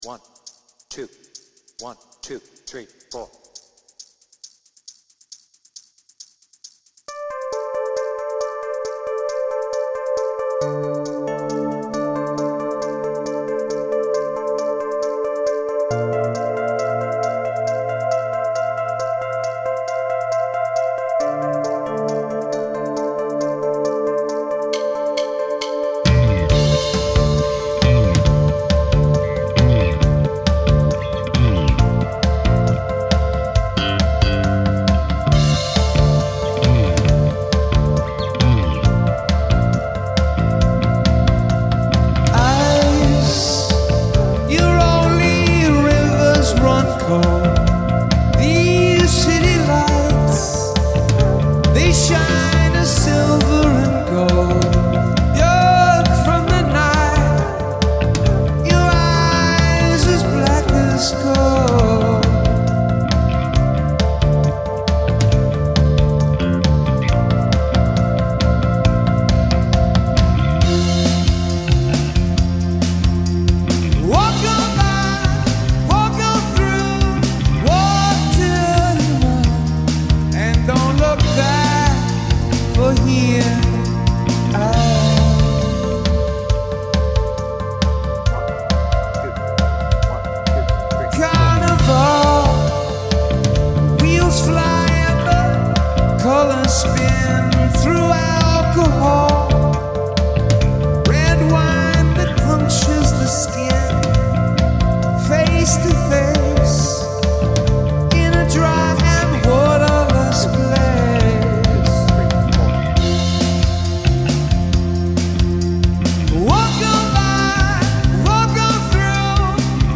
BPM : 136